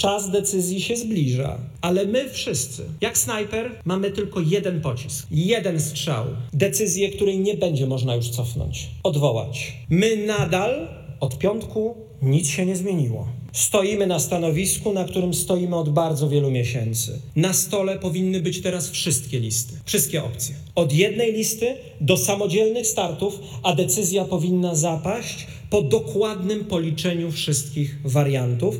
W Łodzi odbywał się dzisiaj Pierwszy Zjazd Krajowy Partii Polska 2050 Szymona Hołowni.